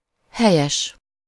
Ääntäminen
IPA: /ˈhɛjɛʃ/